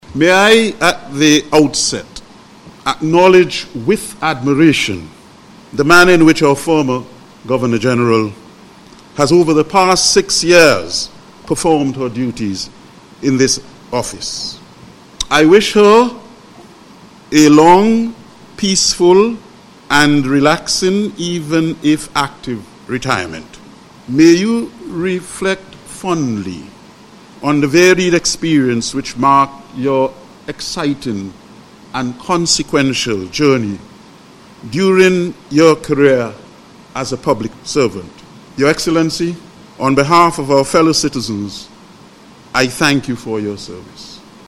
He made the commendation during yesterday’s ceremony which was held to officially install him as this country’s 8th Governor General. He offered thanks to Dame Susan Dougan for her service to the country as and wished her a peaceful and happy retirement.